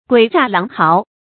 發音讀音
guǐ zhà láng háo